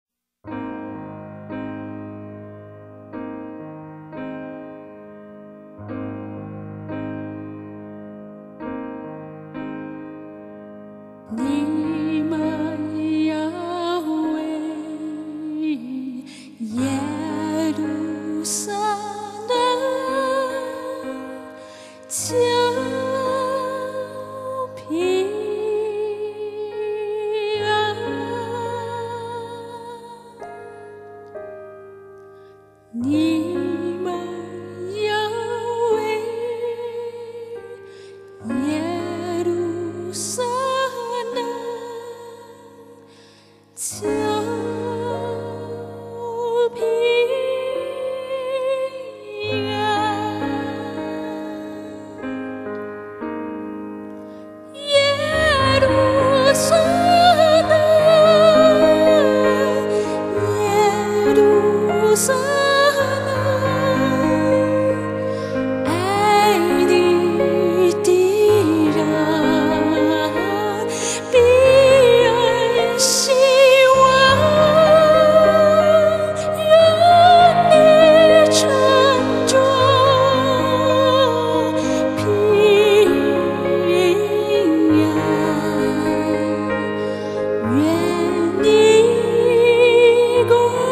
鋼琴、Midi演奏
錄音室：動物園錄音室